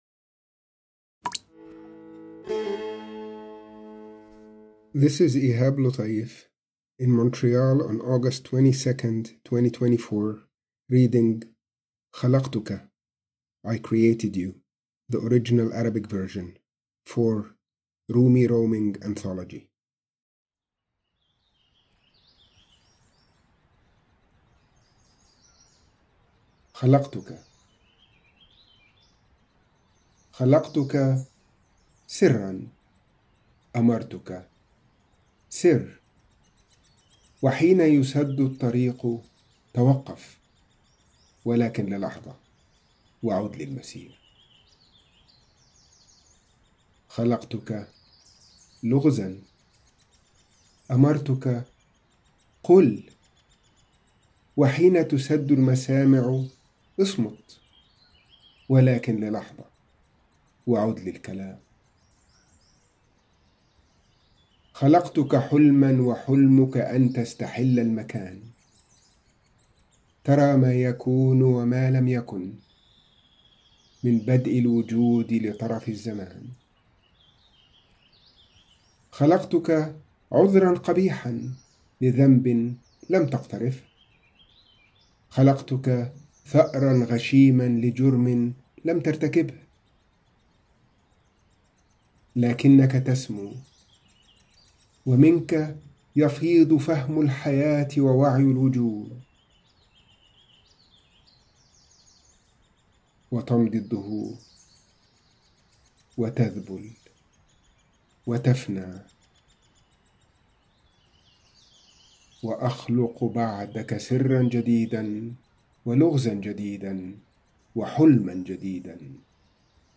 Rumi roaming, Contemporary poetry, Arabic, Diaspora, Spirituality